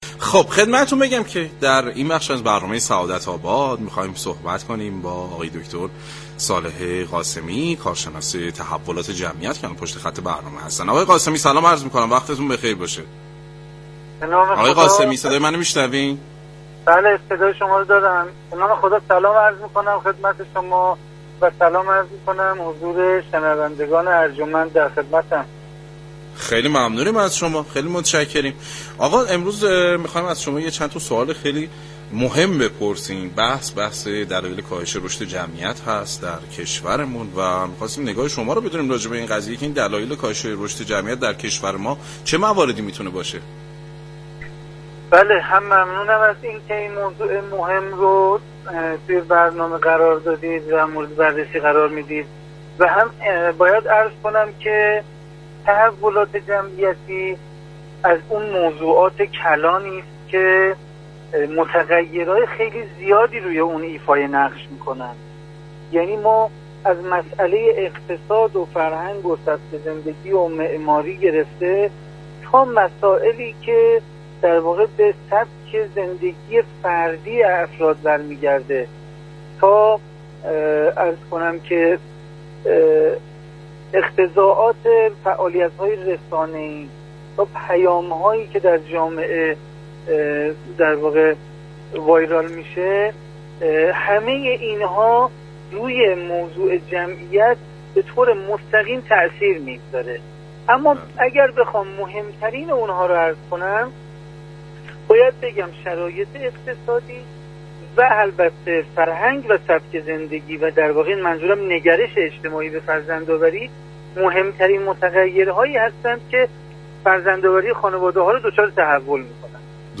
كارشناس تحولات جمعت در گفتگو با سعادت آباد رادیو تهران